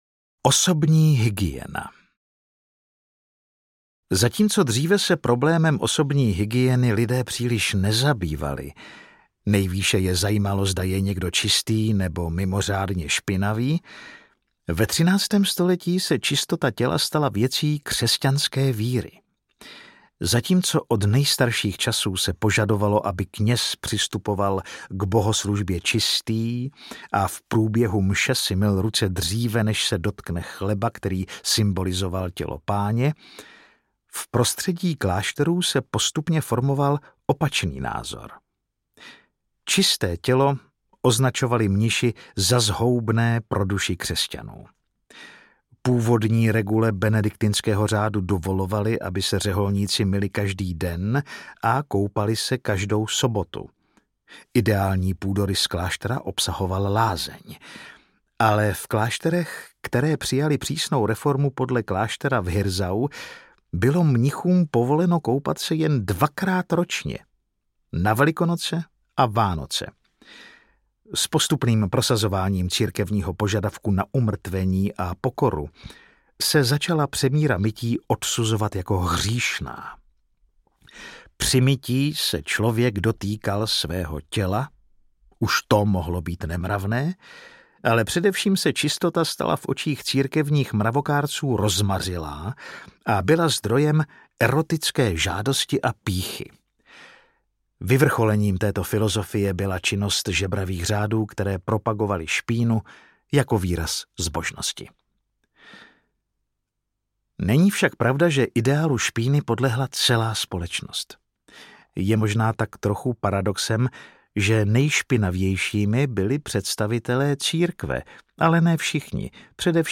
Ukázka z knihy
Vyrobilo studio Soundguru.